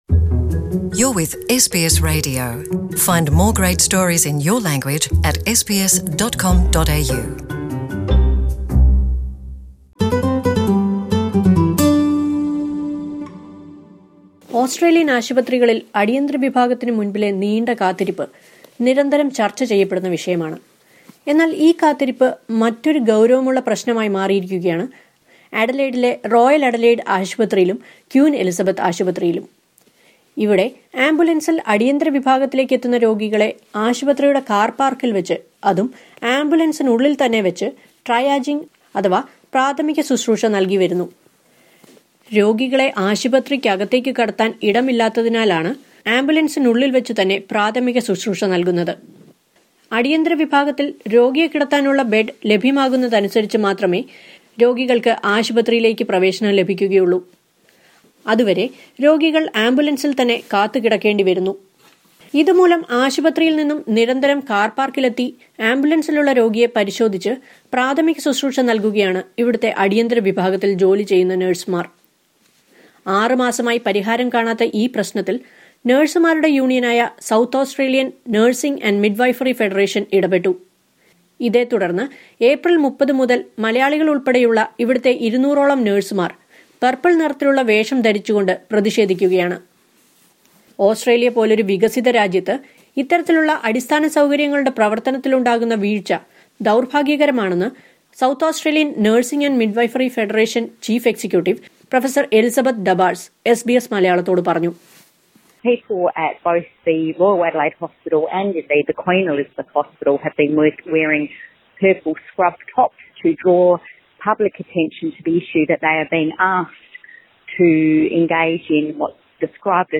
As congestion has forced the Royal Adelaide Hospital authorities to resort to ambulance ramping for triage procedure since the launch of the multi-million facility, the nursing union in the state is continuing its protest wearing purple. Listen to a report.